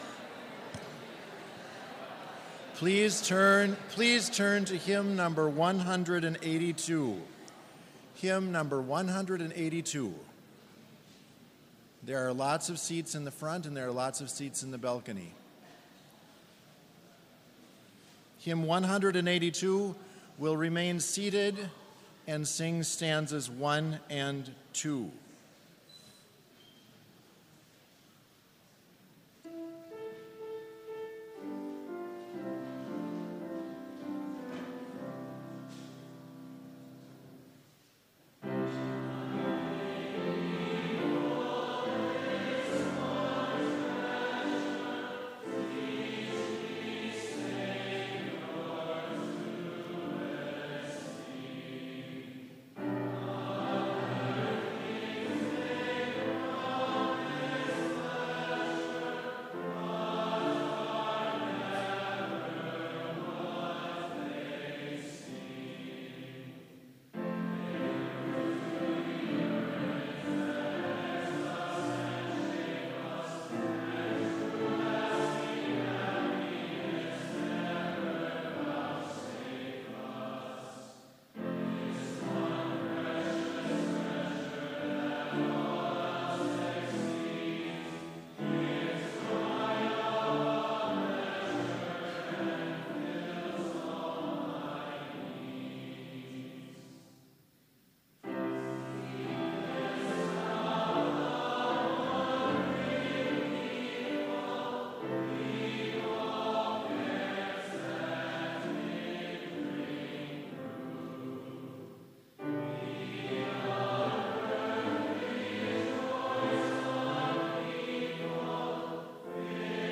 Complete service audio for Chapel - August 23, 2019